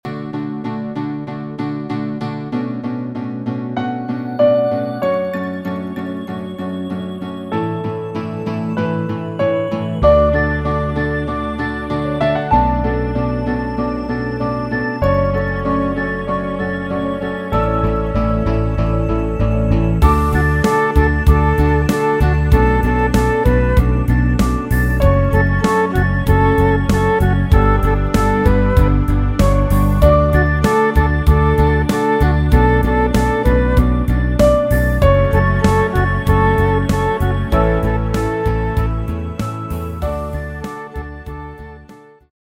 fichier midi